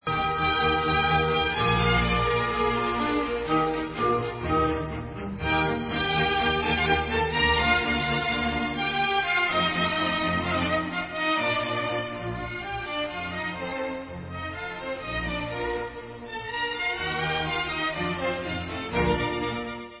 Koncert pro 2 lesní rohy a orchestr č. 1 Es dur